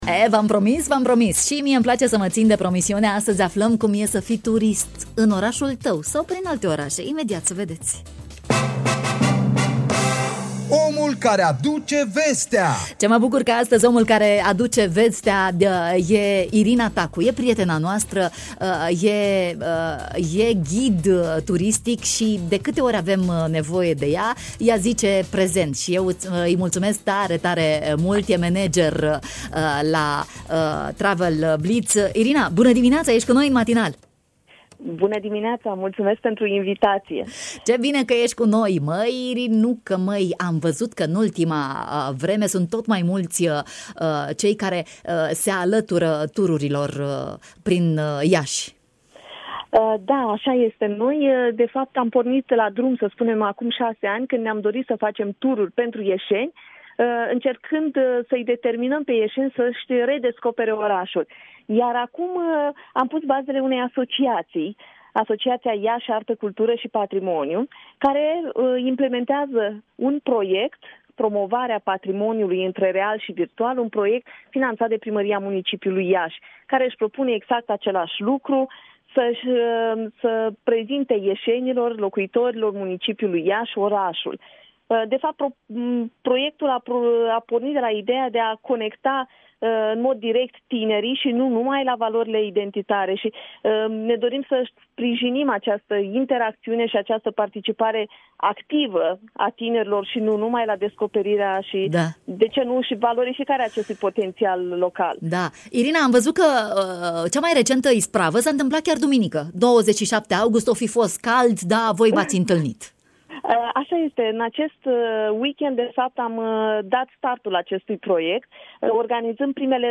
Detalii despre evenimente, în matinal